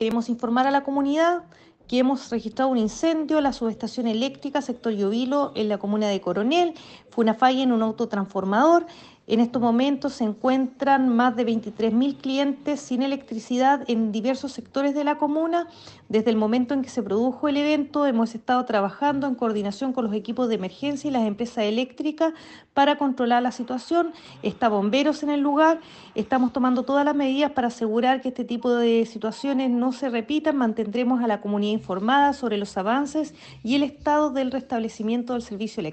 La seremi de Energía de la región del Bío Bío, Daniela Espinoza, entregó más destalles respecto al siniestro.